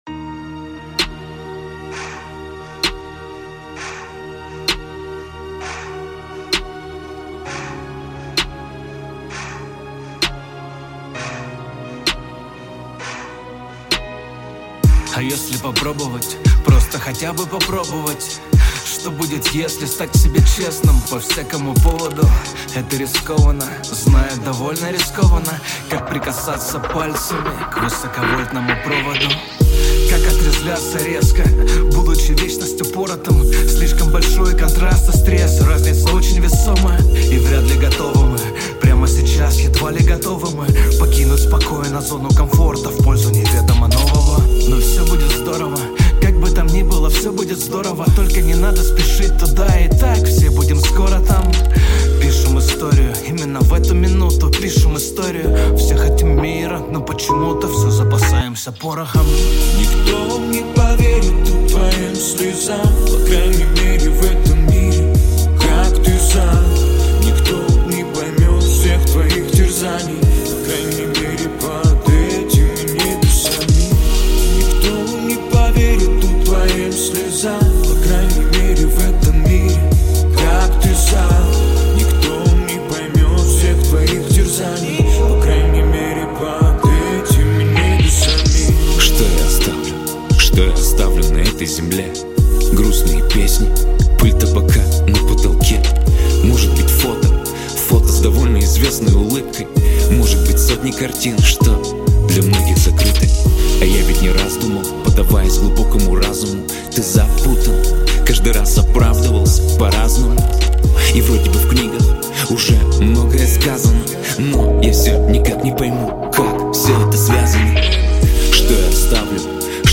Русский рэп
Жанр: Жанры / Русский рэп